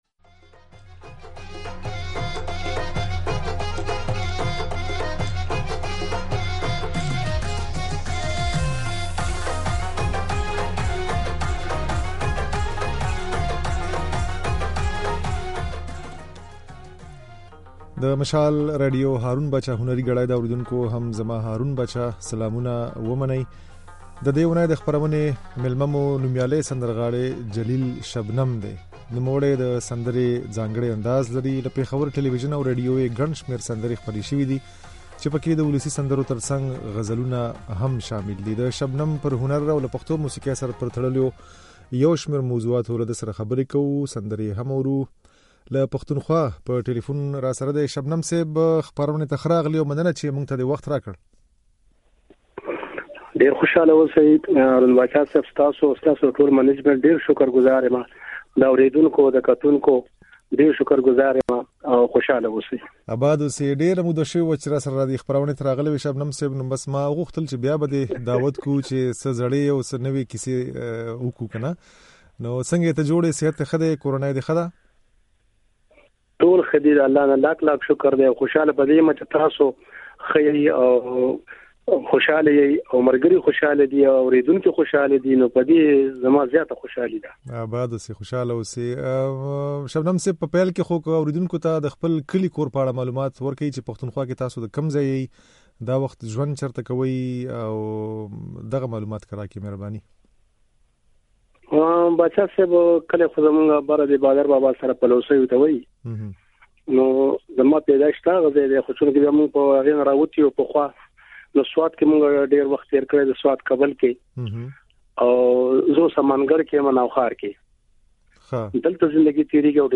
دا اوونۍ مو "هارون باچا هنري ګړۍ" خپرونې ته نوميالی سندرغاړی جليل شبنم مېلمه کړی وو.
د شبنم خبرې او ځينې سندرې يې د غږ په ځای کې اورېدای شئ.